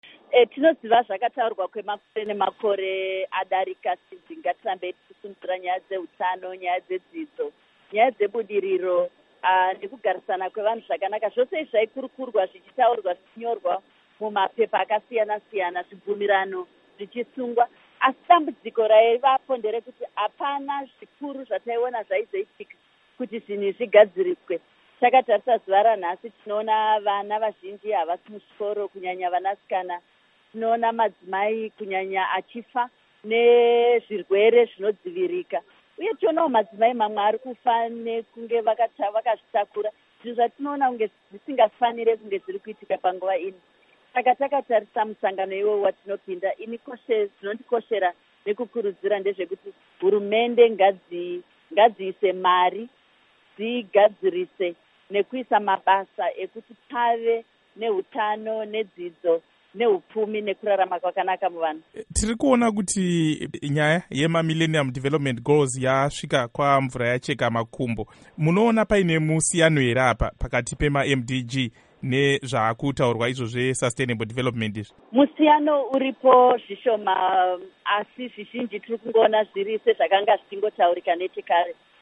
Hurukuro naAmai Nyaradzayi Gumbonzvanda